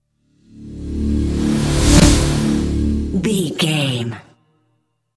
Ionian/Major
Fast
synthesiser
drum machine